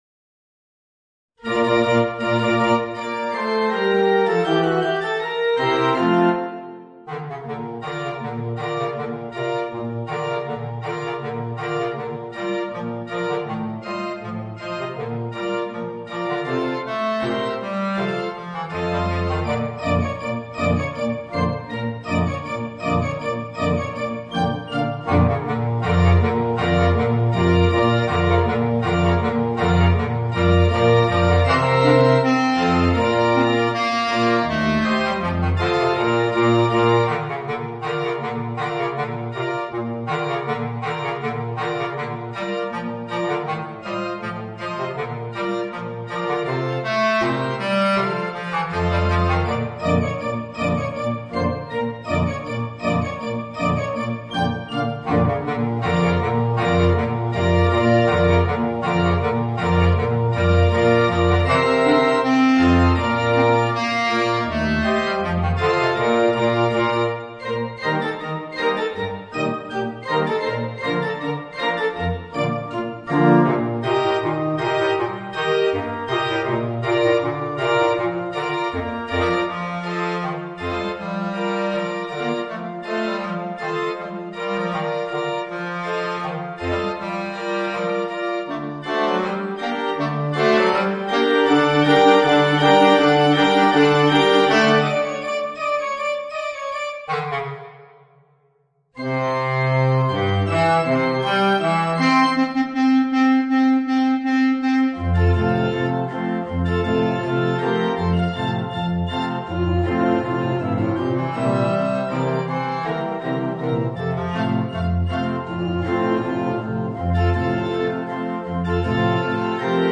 Voicing: Bass Clarinet and Organ